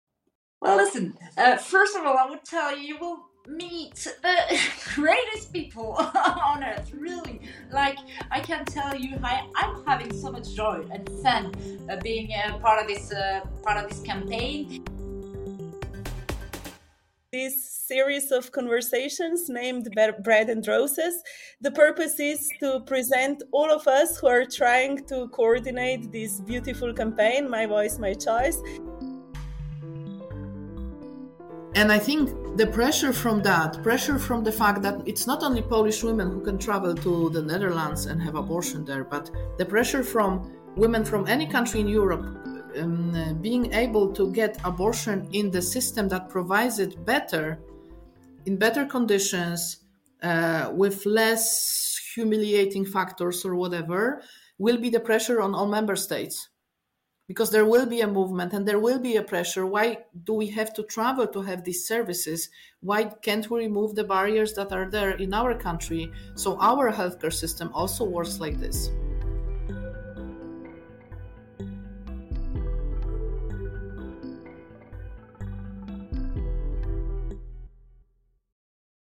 You are invited to listen to conversations among activists from all over Europe to discuss their struggle for justice and their never-ending fight for human rights.